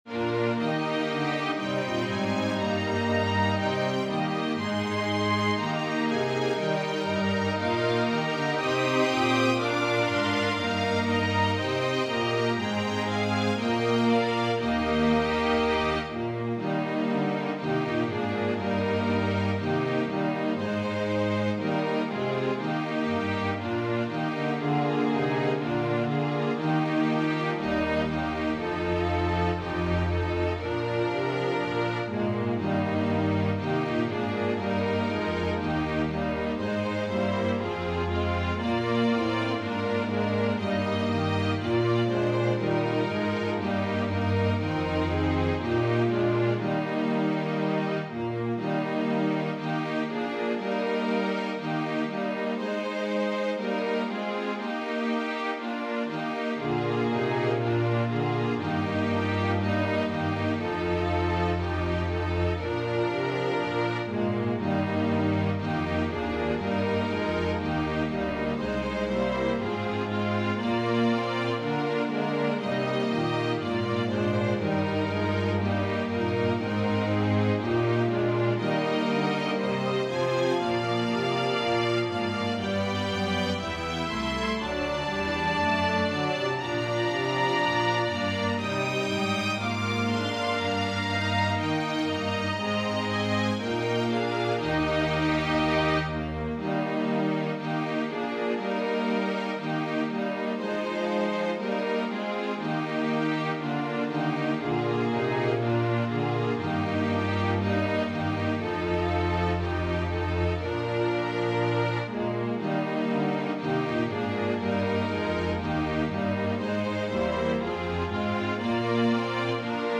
Organ/Organ Accompaniment
Voicing/Instrumentation: Organ/Organ Accompaniment We also have other 2 arrangements of " Awake and Arise ".